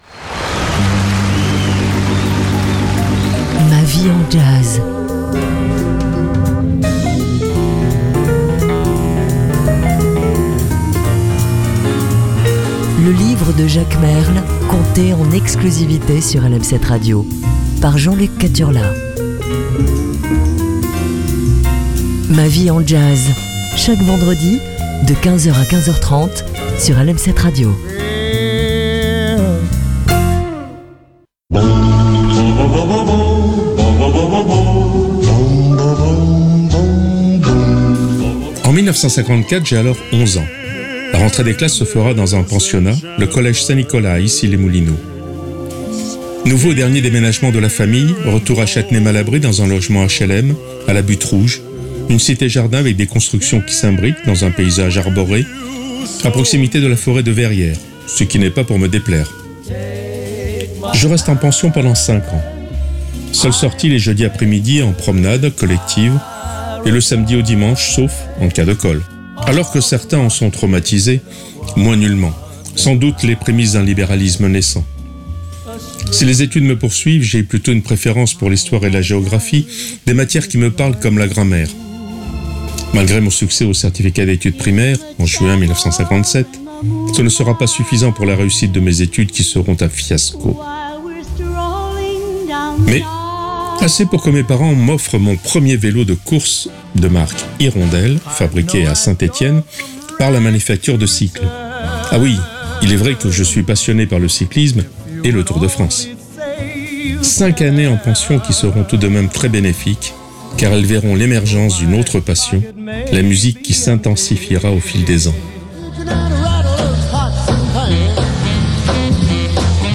jazz & littérature